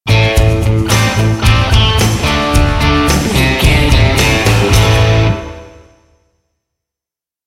Aeolian/Minor
bass guitar
electric guitar
drum machine
aggressive
driving
energetic